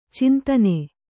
♪ cintane